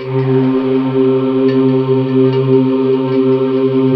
Index of /90_sSampleCDs/USB Soundscan vol.28 - Choir Acoustic & Synth [AKAI] 1CD/Partition C/12-LIVES